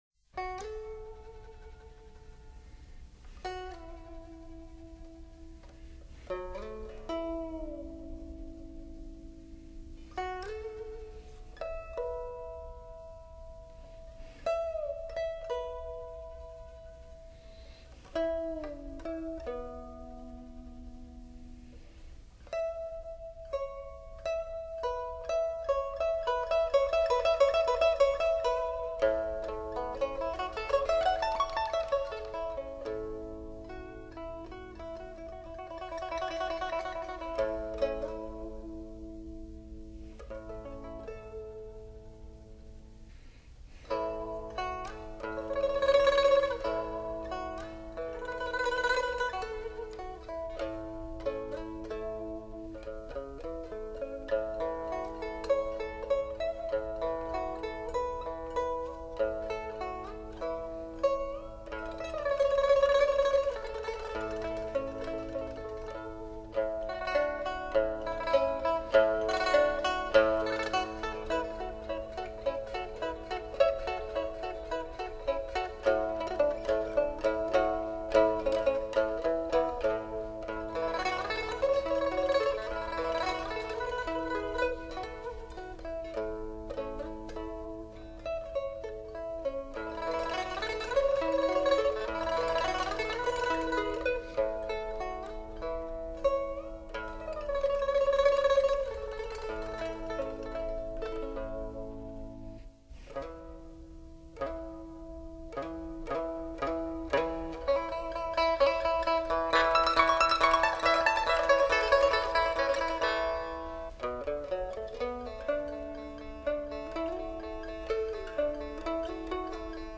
音乐类型：中国民乐